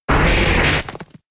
Cri de Grolem K.O. dans Pokémon X et Y.